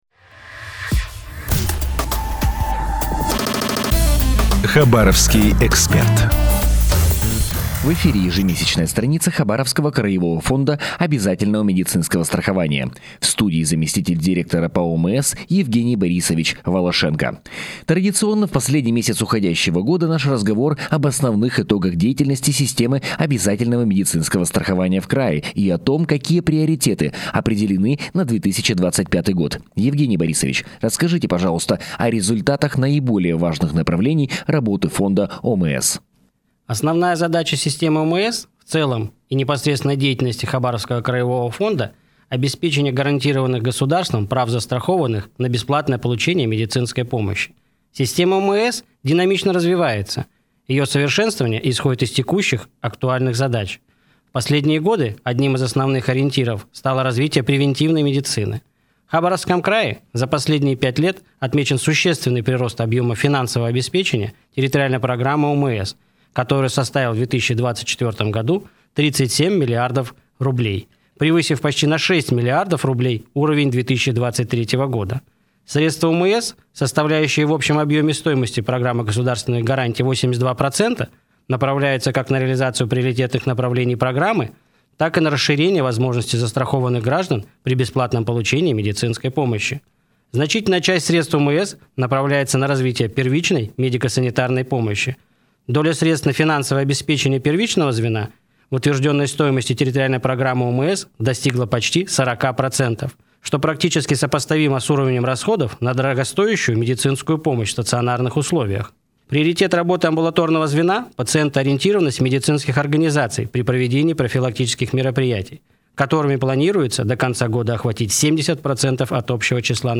Выступление на радио